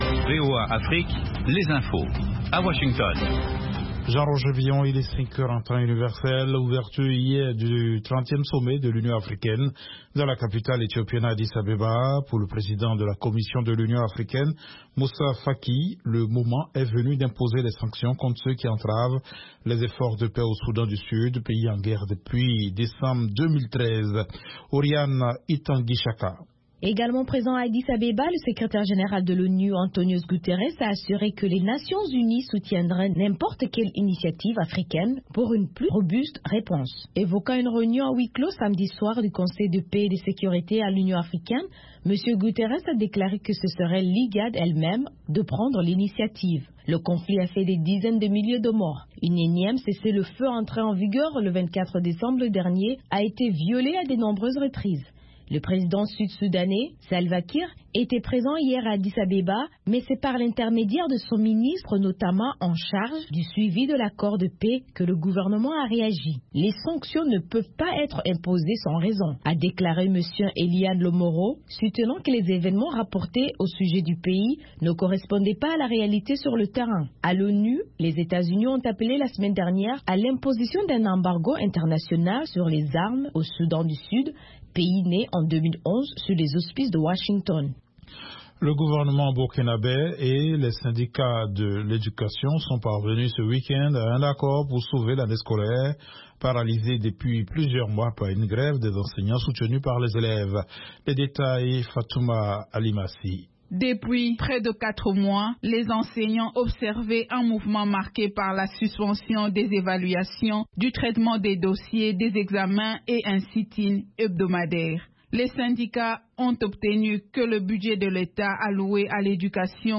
5 min News French